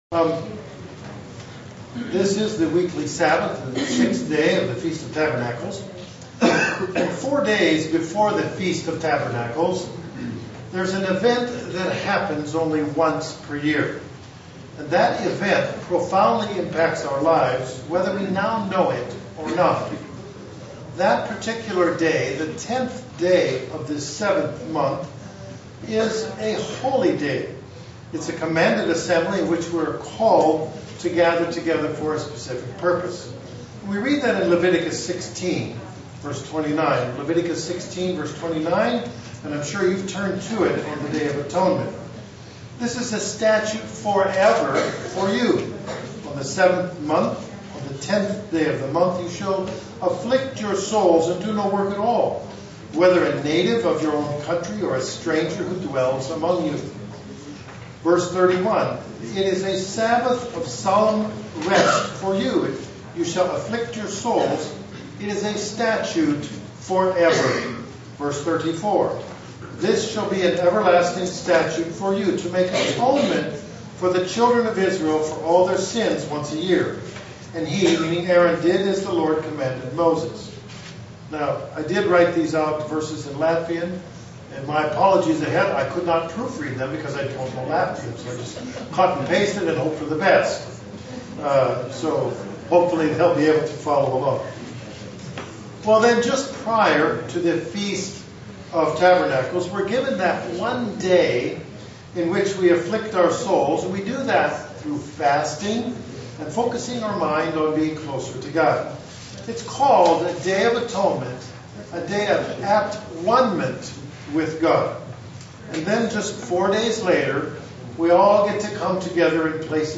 Given in Buffalo, NY
Print The sixth day of the Feast of Tabernacles in Estonia SEE VIDEO BELOW UCG Sermon Studying the bible?